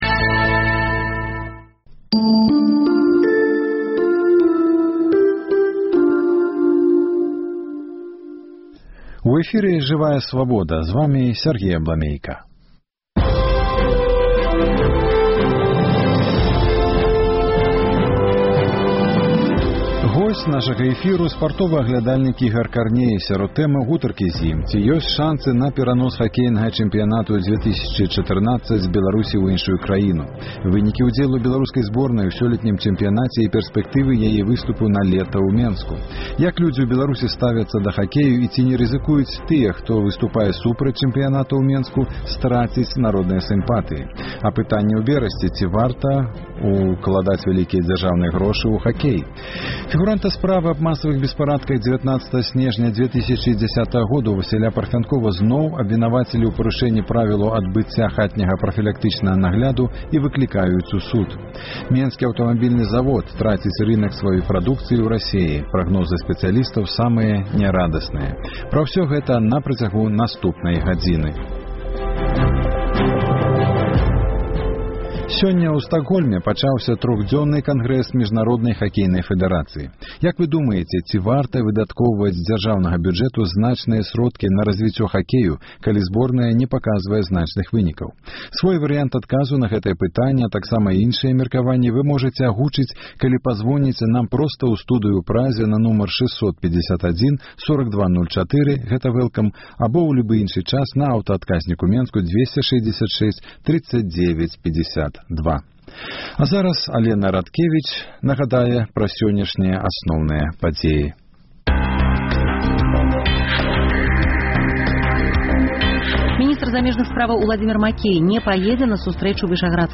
Апытаньне ў Берасьці. Ці варта ўкладаць вялікія дзяржаўныя грошы ў хакей?